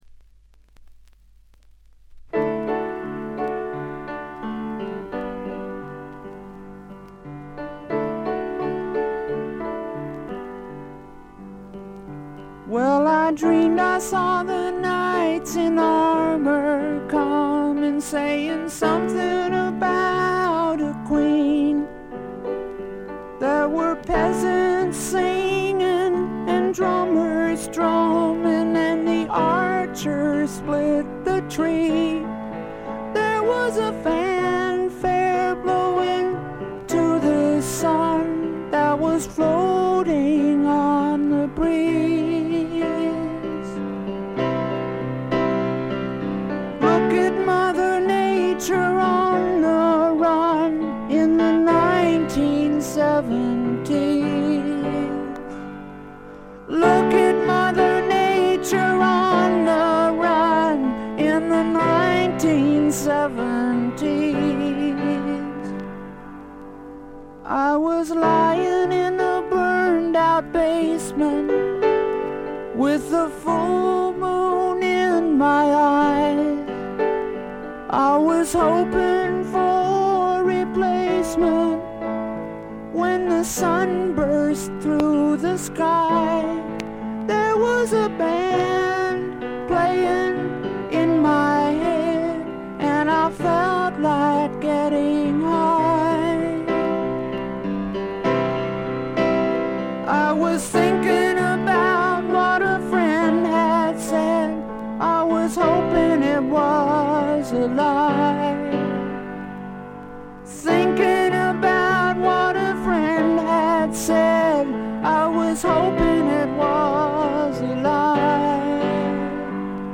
試聴曲は現品からの取り込み音源です。
piano
flugelhorn